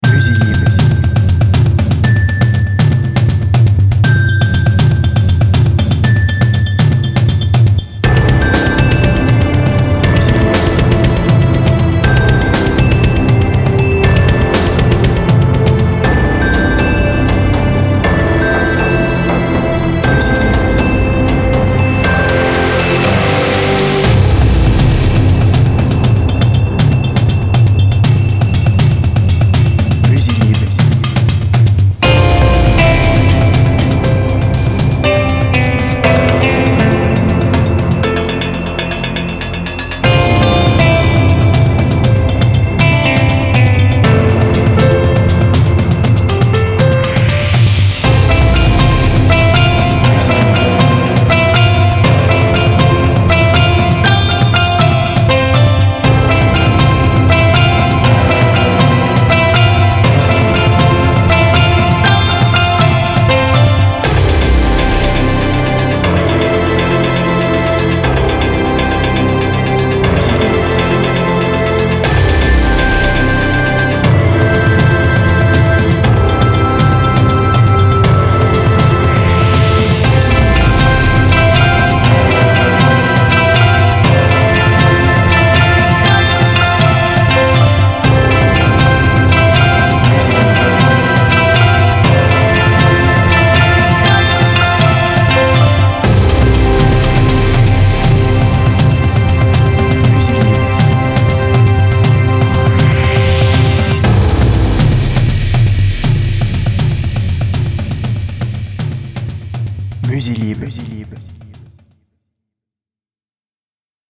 BPM Rapide